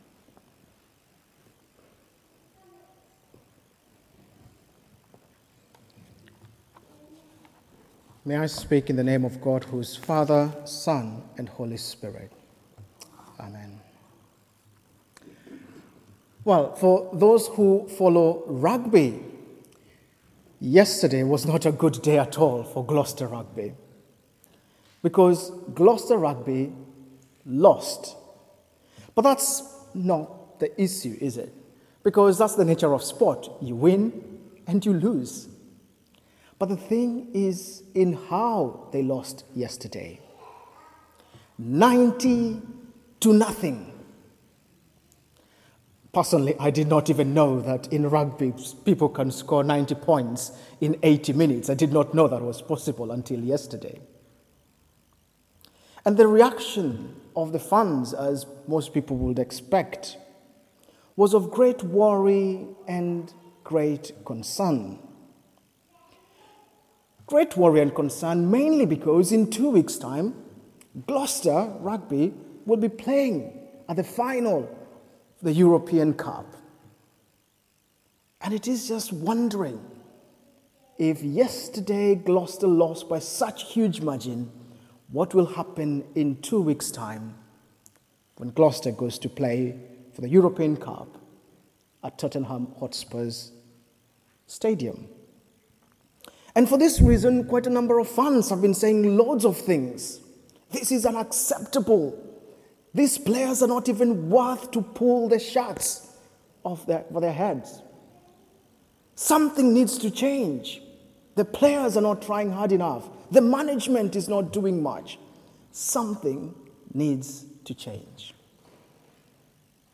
Sermon: Waiting for the Spirit | St Paul + St Stephen Gloucester